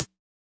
UHH_ElectroHatC_Hit-34.wav